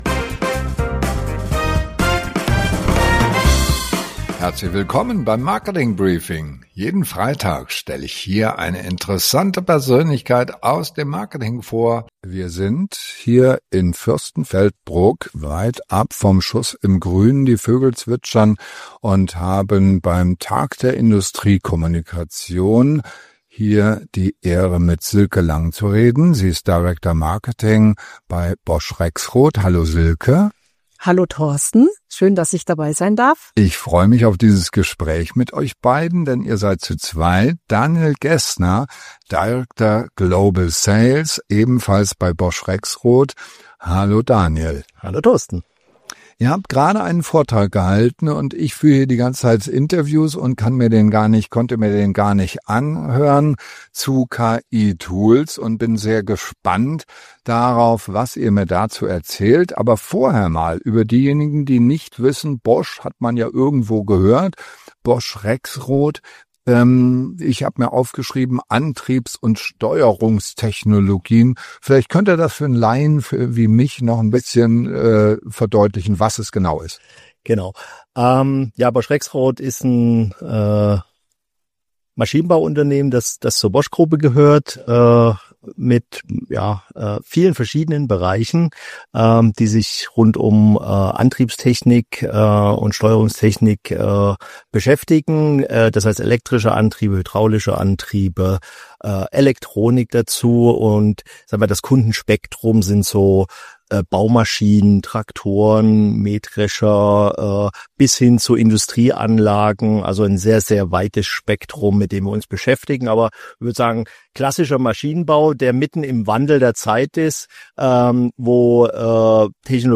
Jeden Freitag Interviews mit spannenden Persönlichkeiten aus der Digital- & Marketing-Szene